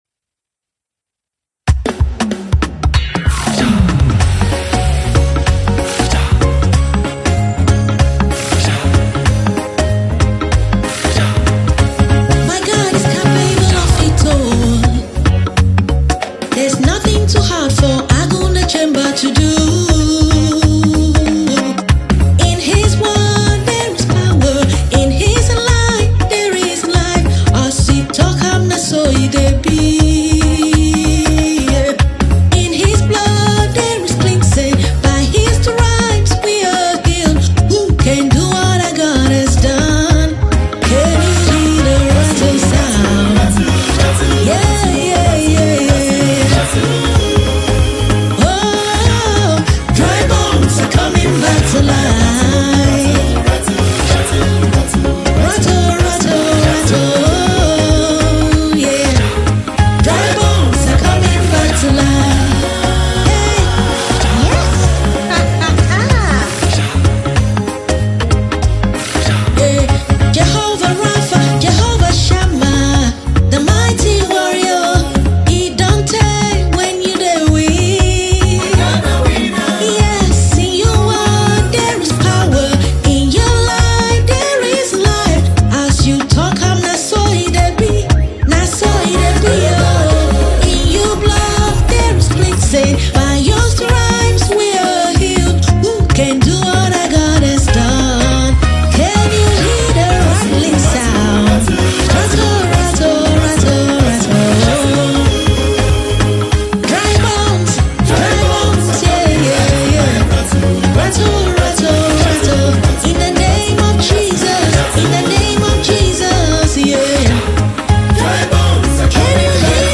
Nigerian gospel music minister and exquisite songwriter